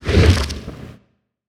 journal_open.wav